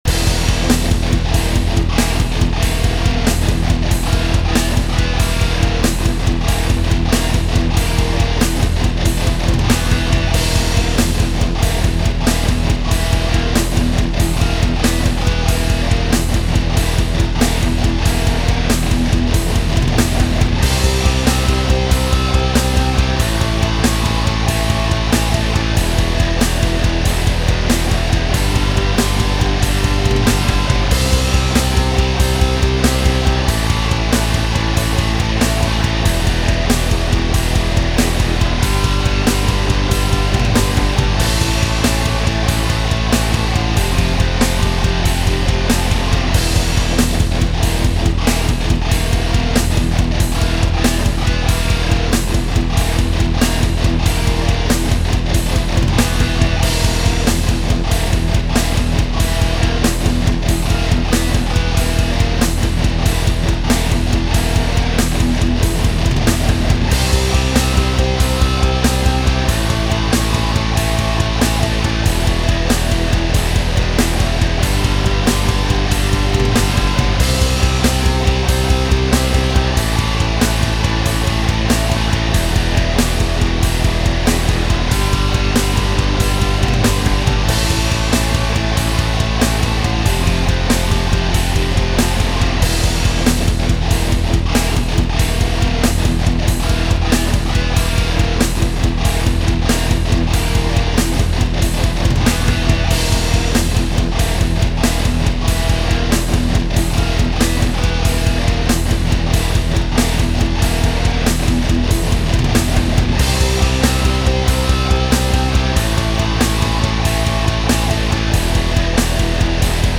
First ever original song(inst.only)